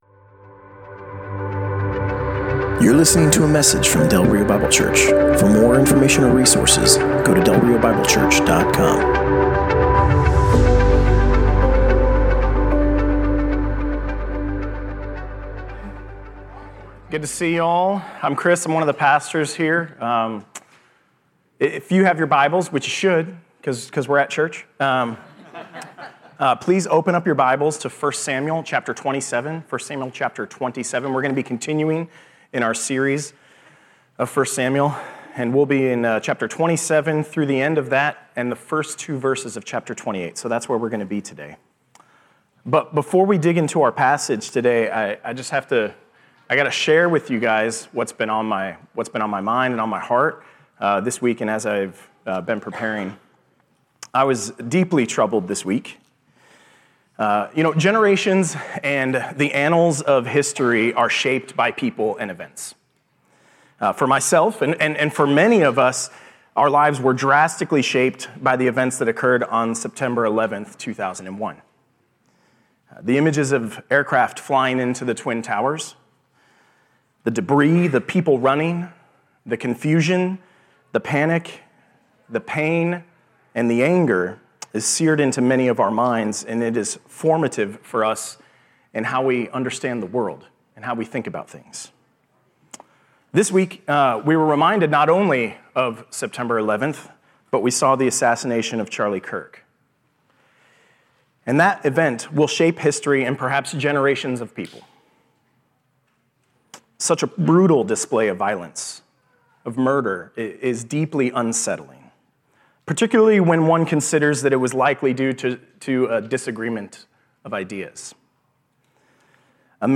Passage: 1 Samuel 27: 1-28:2 Service Type: Sunday Morning